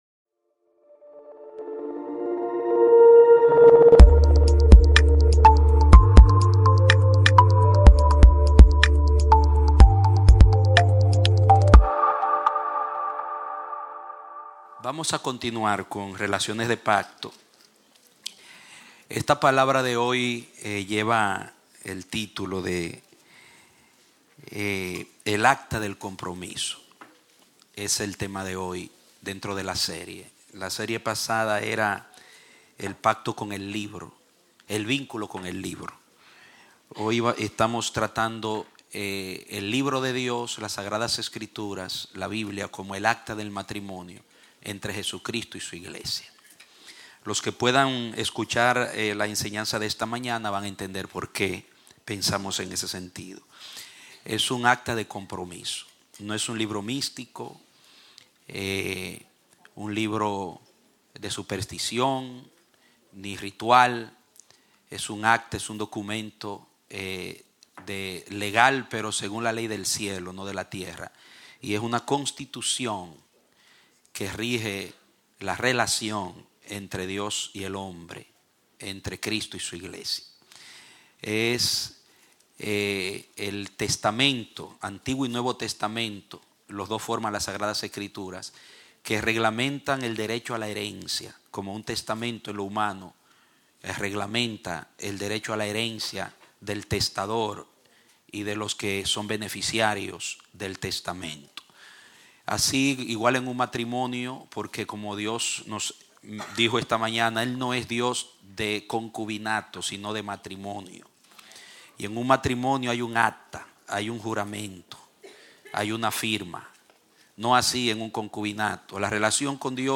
Un mensaje de la serie "Mensajes."